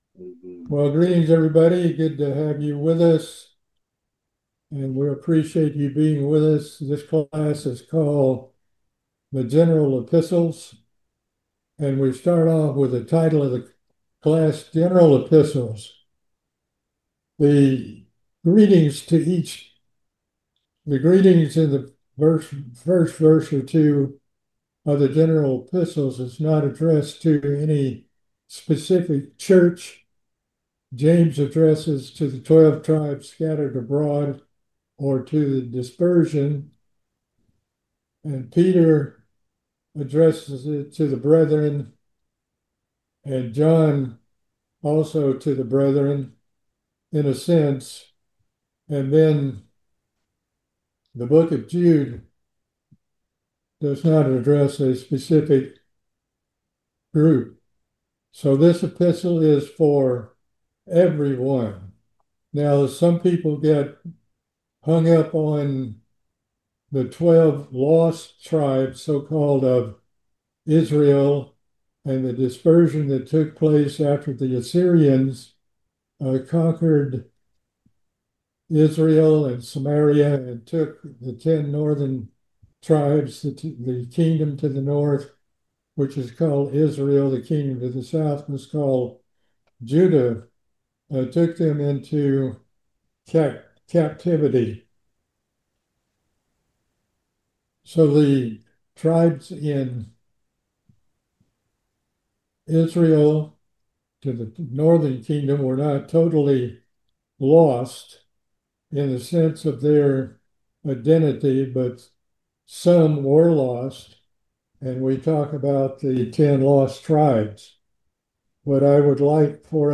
We are starting a new bible study series on the General Epistles.